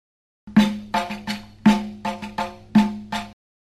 (Rhythm realicon), or three beats
05 4Samaii 3 Rhythm.mp3